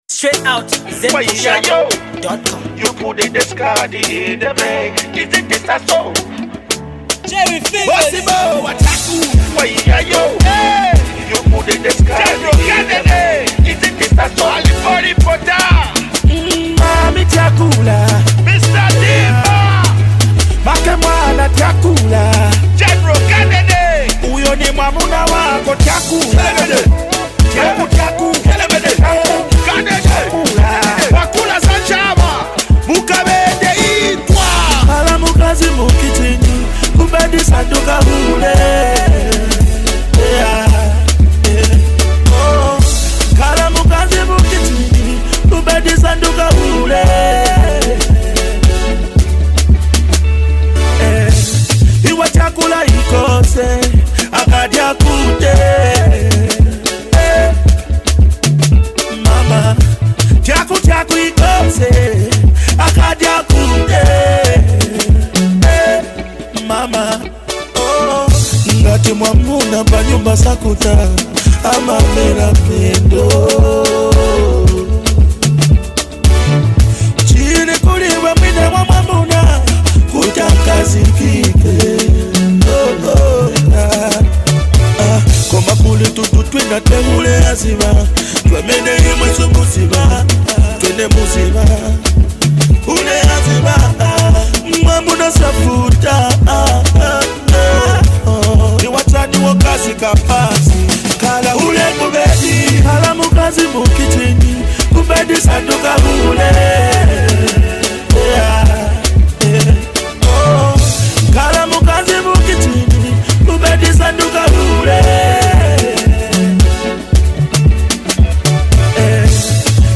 Get the hip-hop joint below and enjoy !